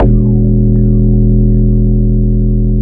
U F O BASS-R.wav